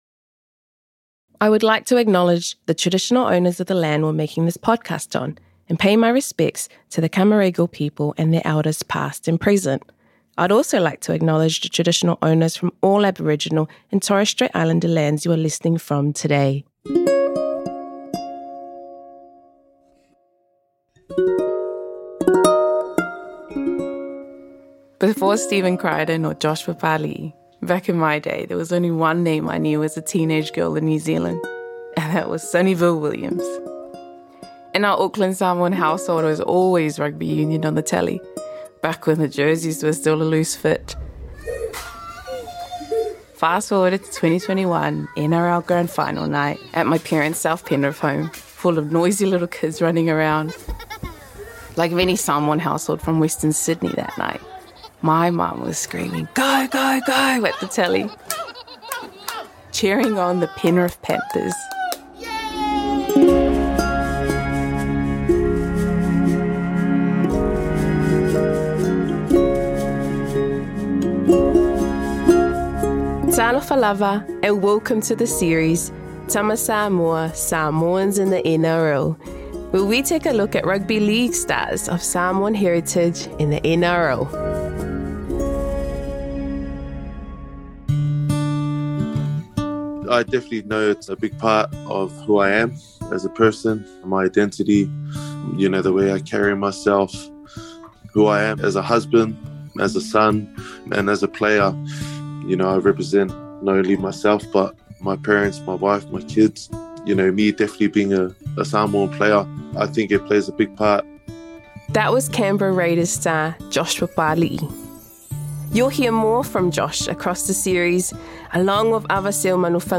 In this first episode of Tama Samoa, current and former NRL players reflect on family, cultural identity, achieving success, and challenges on and off the field.